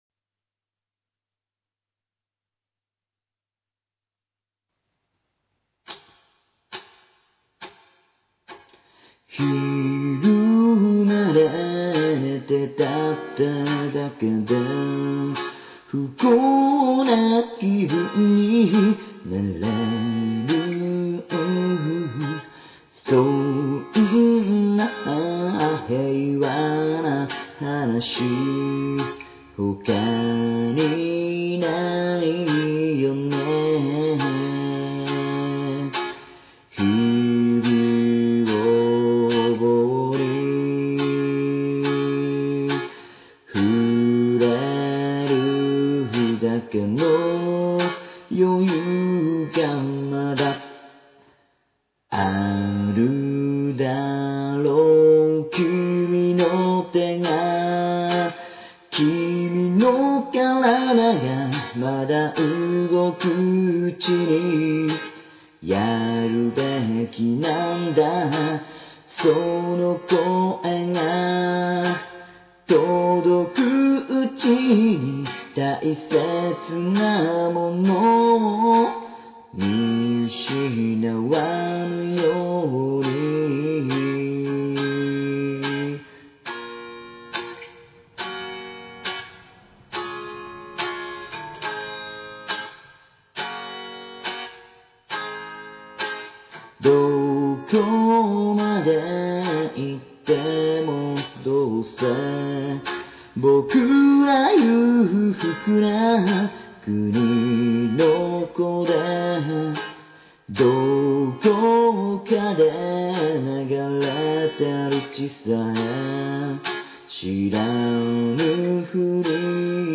音源は、自宅でコソコソと録音したものです。